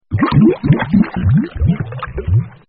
Som das Bolhas -
Bolhas
Bolhas.mp3